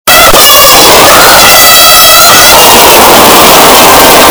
Hog Rider Super Alto - Botón de Efecto Sonoro